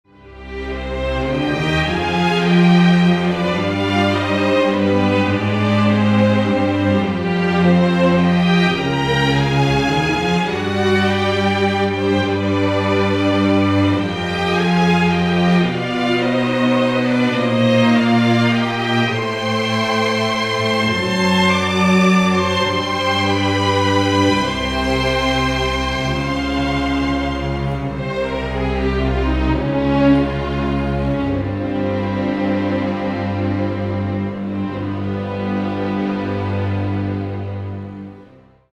• Качество: 192, Stereo
без слов
инструментальные